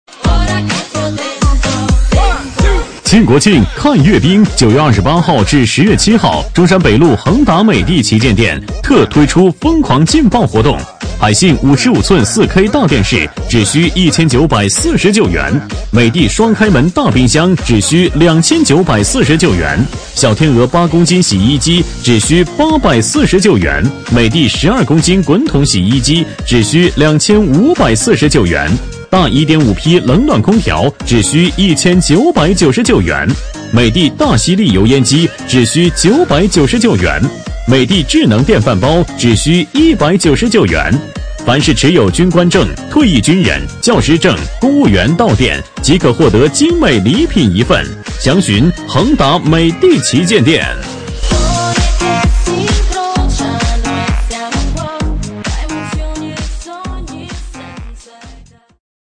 【男19号促销】美的旗舰店
【男19号促销】美的旗舰店.mp3